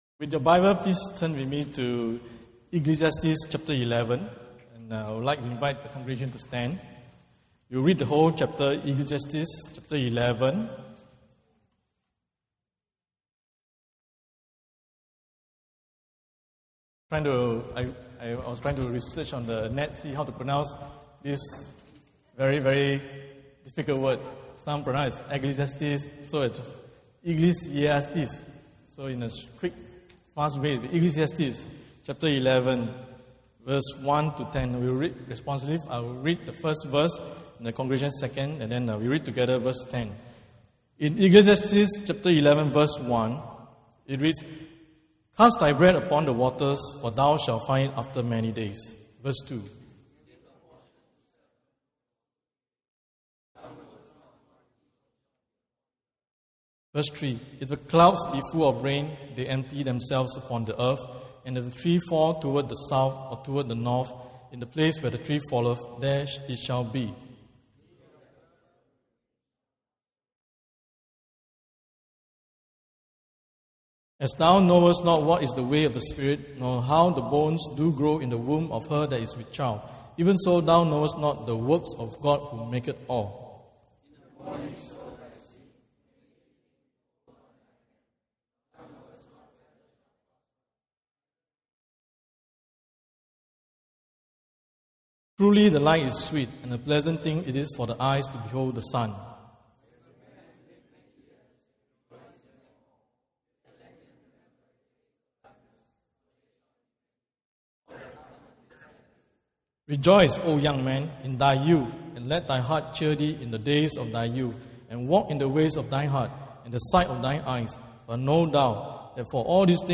Sunday Worship Service